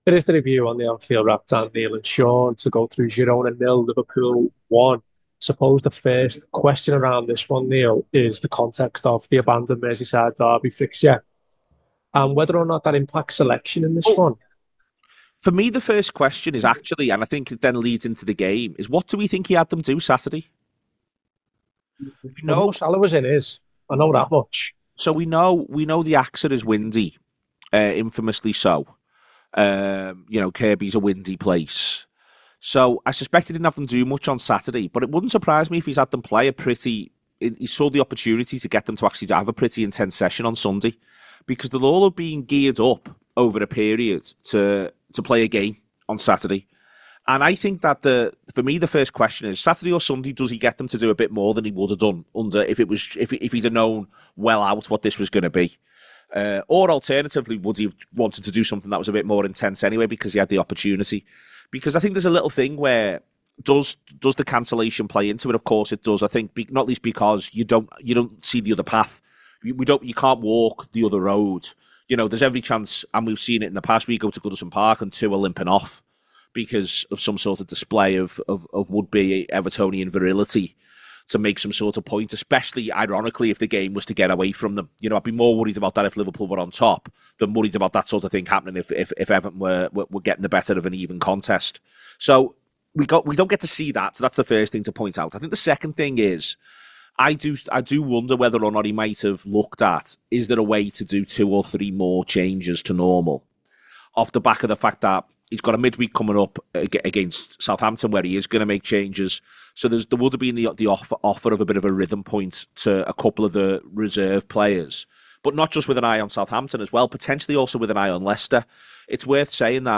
Below is a clip from the show – subscribe for more review chat around Girona 0 Liverpool 1…